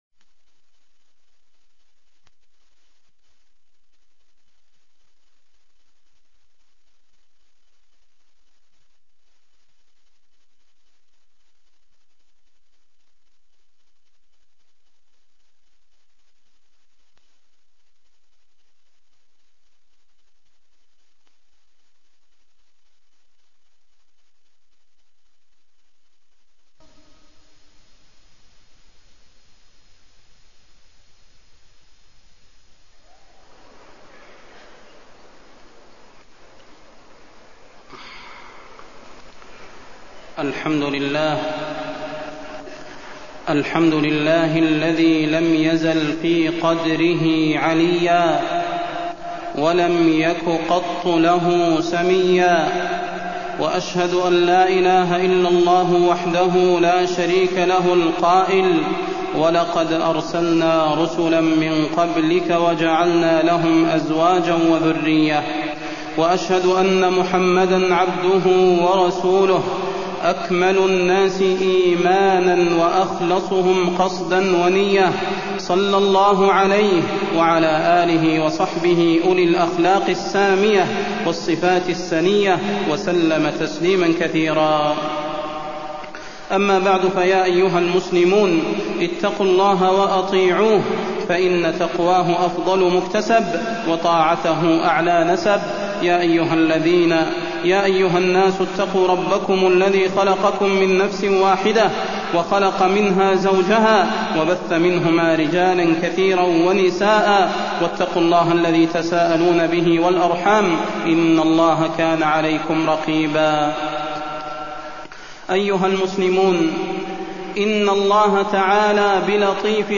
فضيلة الشيخ د. صلاح بن محمد البدير
تاريخ النشر ١١ جمادى الأولى ١٤٢٤ هـ المكان: المسجد النبوي الشيخ: فضيلة الشيخ د. صلاح بن محمد البدير فضيلة الشيخ د. صلاح بن محمد البدير الزواج